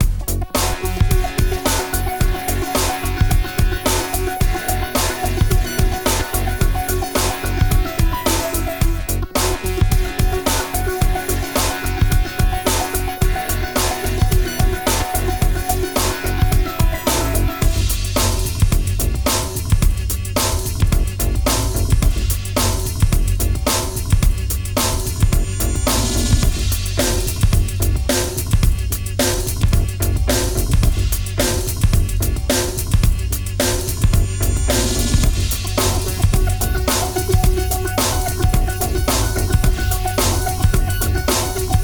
Tecno rock (bucle)
tecno
repetitivo
rock
sintetizador
Sonidos: Música